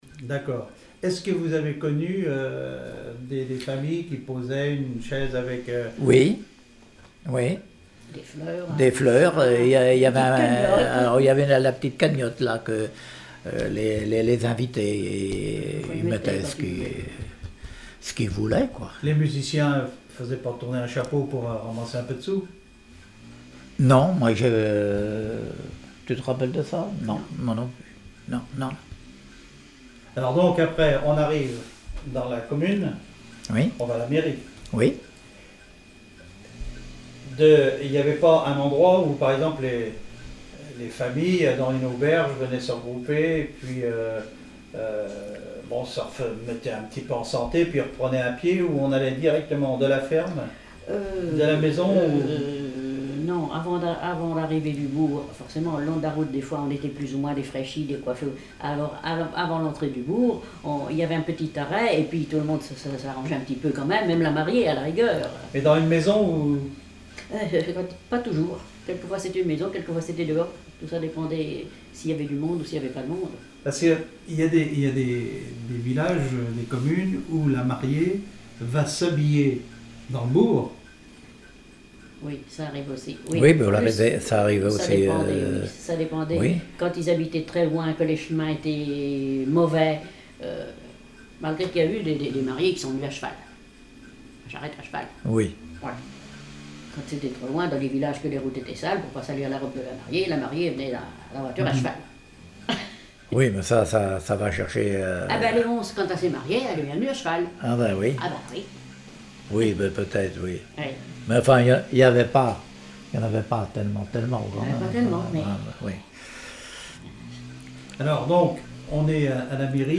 Témoignage comme joueur de clarinette
Catégorie Témoignage